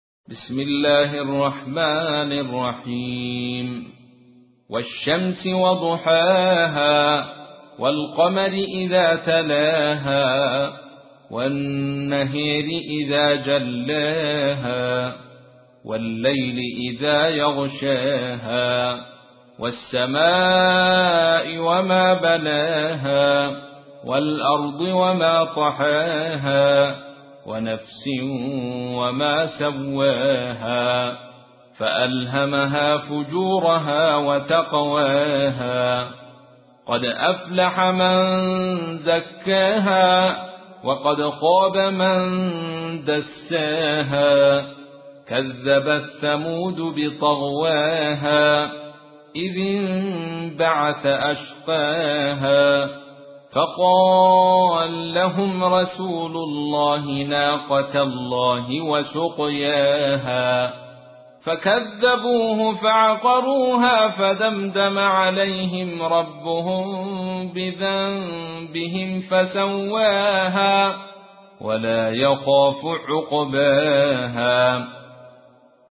تحميل : 91. سورة الشمس / القارئ عبد الرشيد صوفي / القرآن الكريم / موقع يا حسين